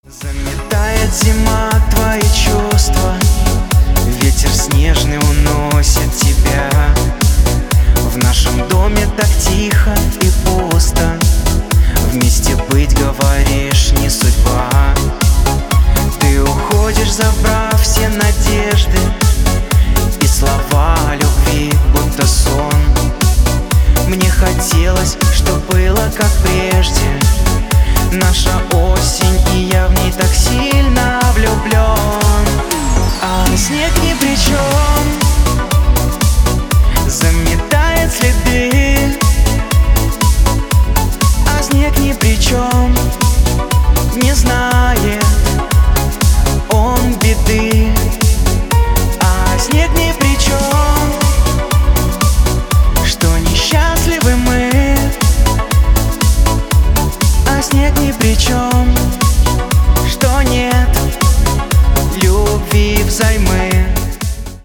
Категория: Шансон рингтоны